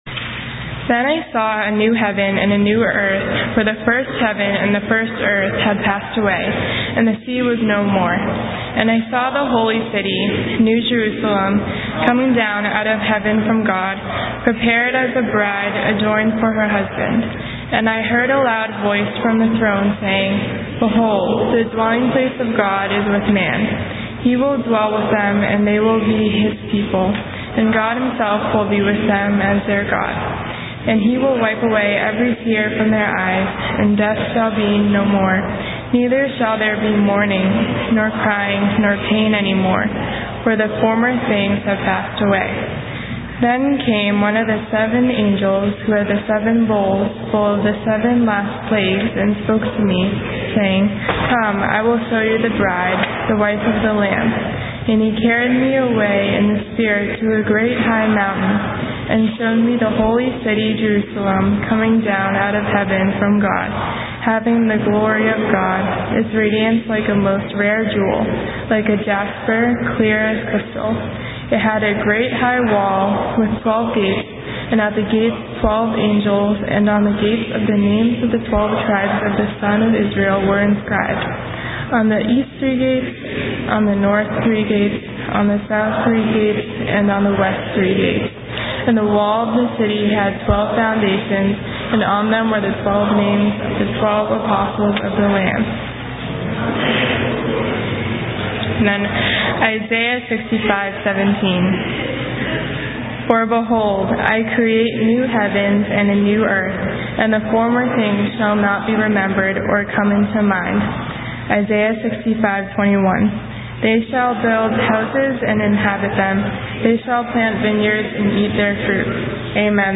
Tag: Recent Sermons - Page 140 of 178 | Boston Chinese Evangelical Church